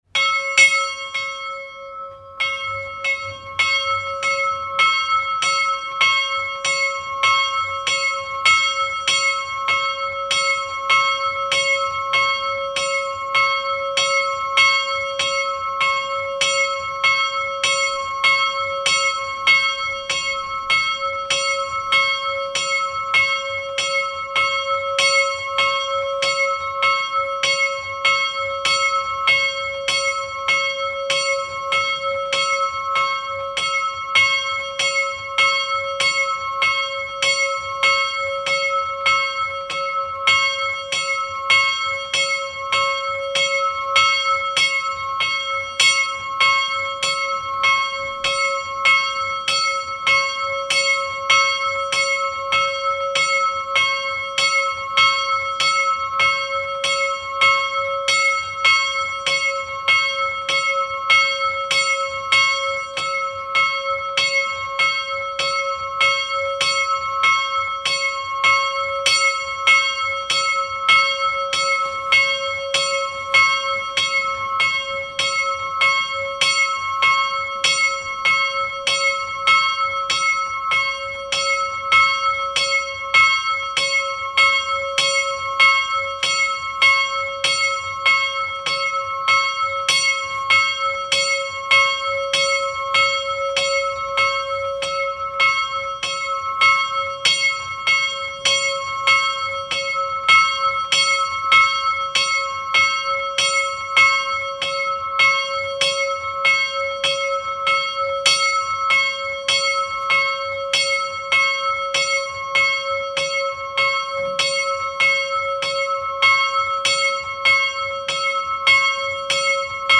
Zvon z Jeníkova v rozhlase
Jeníkov-nahrávka-zvonu-2017-Český-rozhlas-ČB.mp3